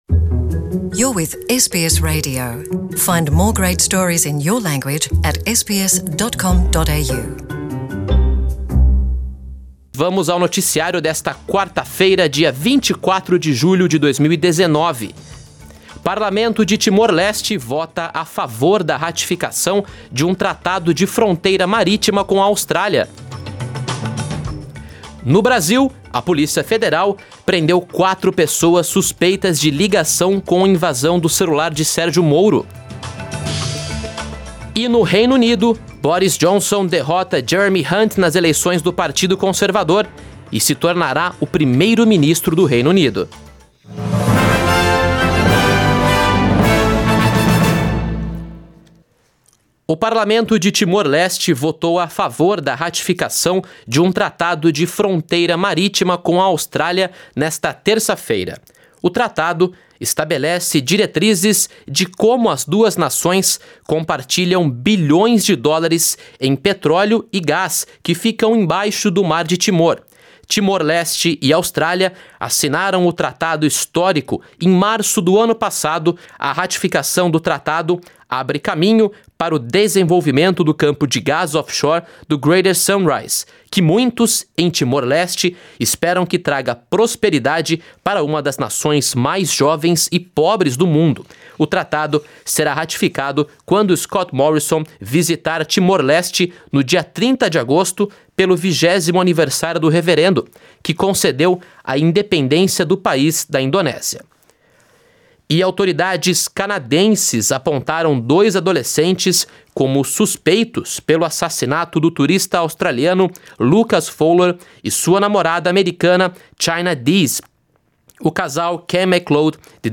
São as notícias da Austrália e do Mundo da rádio SBS para esta quarta-feira, 24 de julho de 2019.